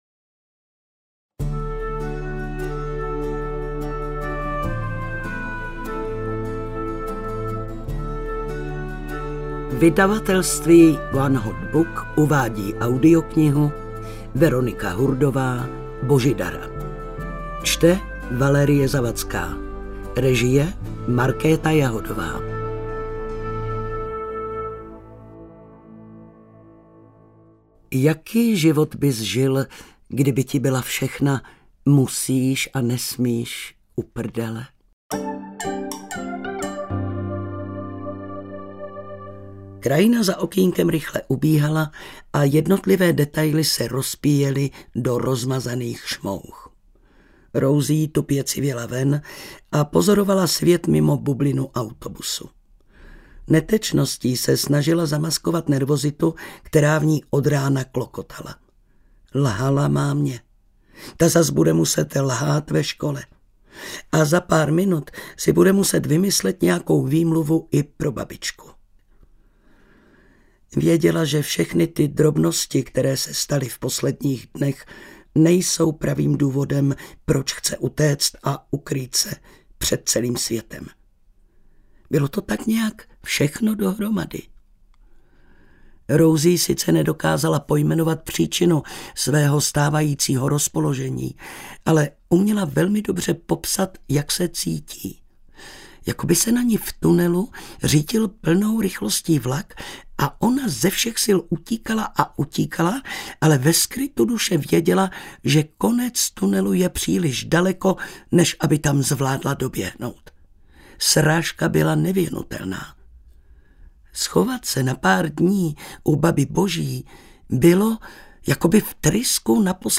Božidara audiokniha
Ukázka z knihy